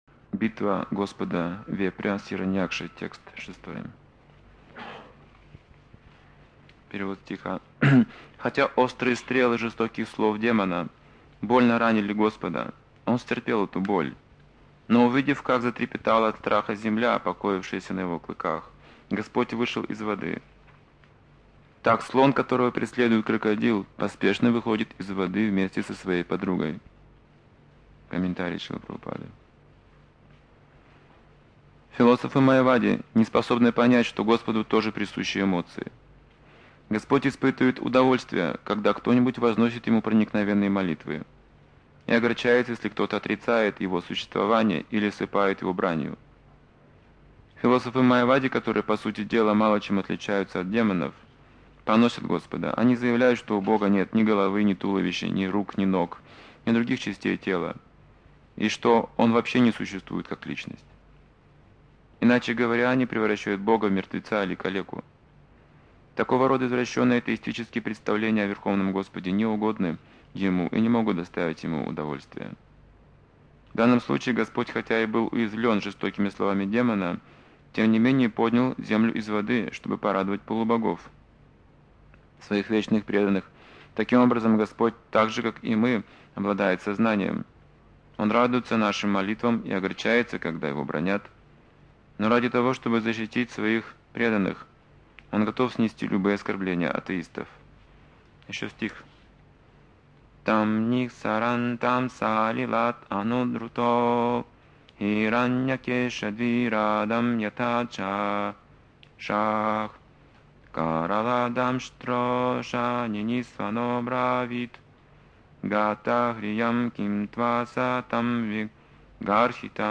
Темы, затронутые в лекции: Желание - основа действий Как возникают разногласия Майавади принимают Веды, но исключают Бога Непогрешимость Верховной Личности Бога Позиция духовного учителя Ошибки на духовном пути Энтузиазм - признак преданного служения Совершенство - постоянное желание служить Кришне Недоверие преданных Как проводить экадаши Почему Майа беспокоит преданных Служение в соответствии со своей